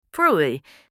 کلمه “نان” به آلمانی و تلفظ آن (Brot)
bread-in-german-1.mp3